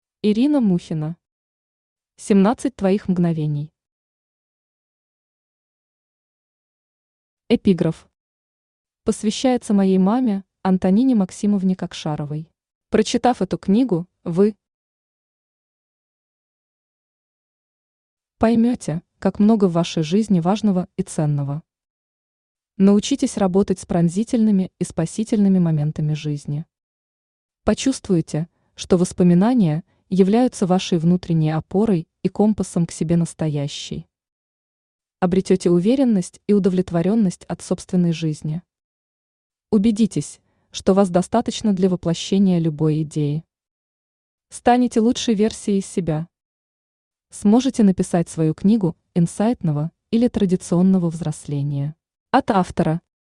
Aудиокнига 17 твоих мгновений Автор Ирина Мухина Читает аудиокнигу Авточтец ЛитРес.